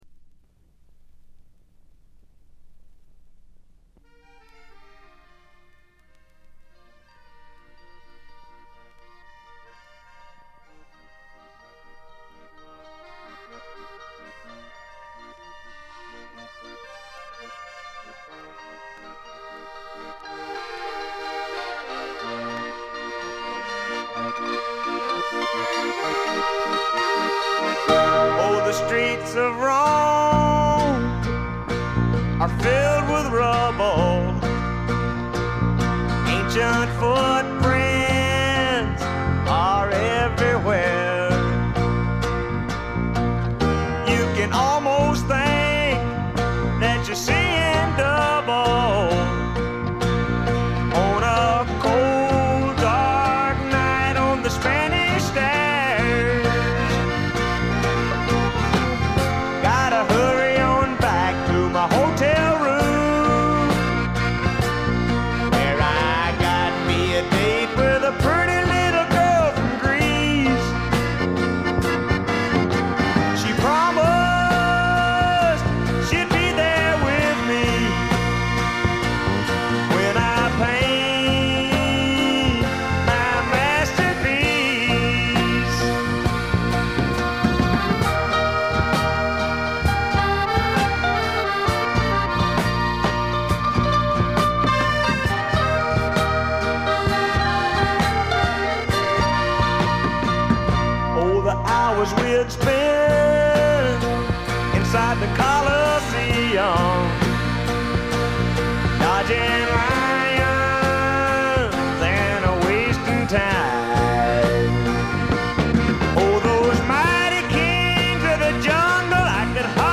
部分試聴ですが、ごくわずかなノイズ感のみ。
試聴曲は現品からの取り込み音源です。